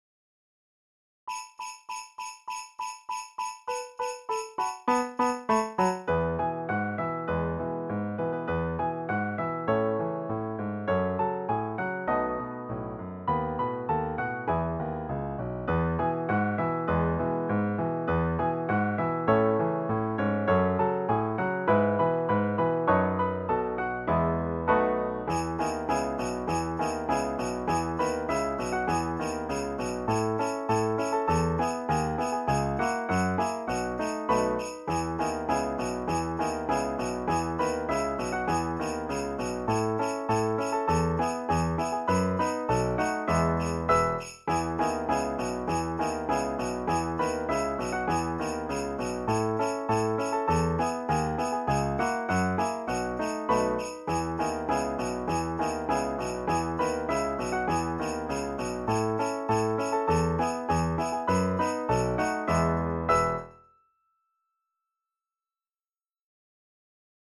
Sheet Music - Vocals JINGLE BELLS-WORDS.pdf Sheet Music jingle-bells.pdf ALL audio Sop I audio Sop II audio Alto audio VA jingle-bells_key-of-E.mp3 rehearsal recording rehearsal recording rehearsal recording YouTube
jingle-bells_key-of-E.mp3